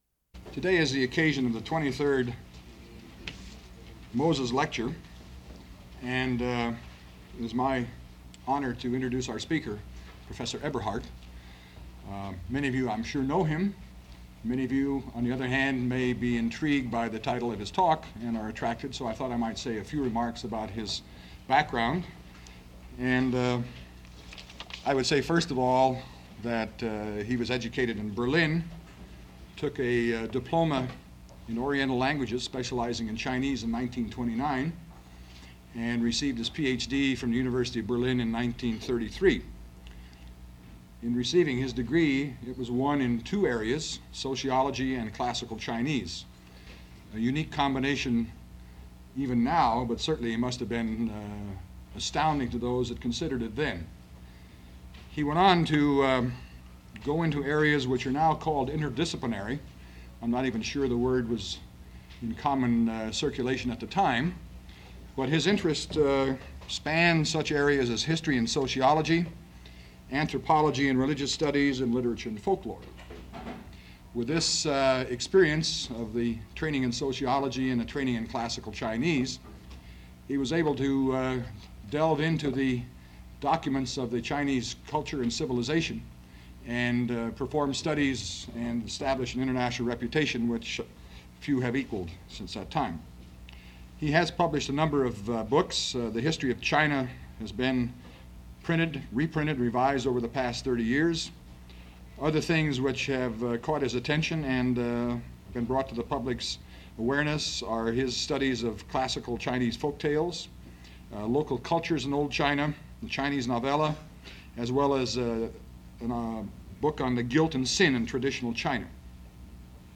The Inscrutable Chinese-Can We Understand Them? - Berkeley Graduate Lectures